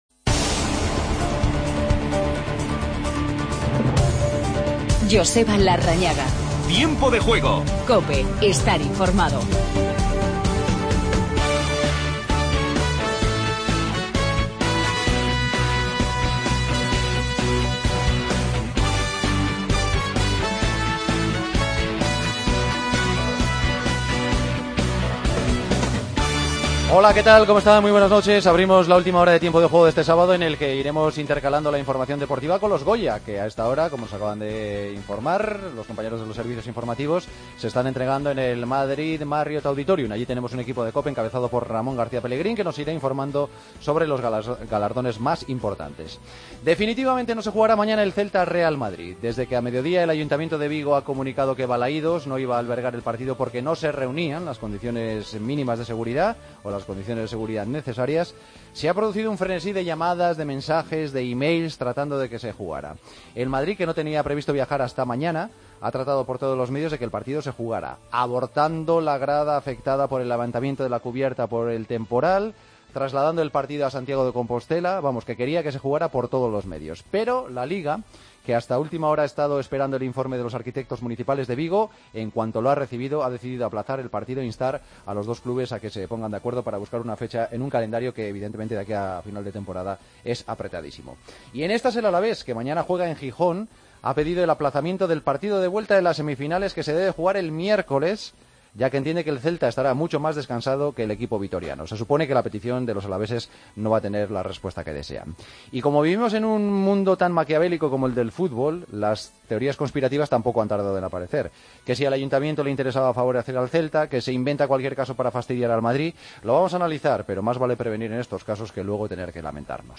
Hablamos con el alcalde de Vigo, Abel Caballero.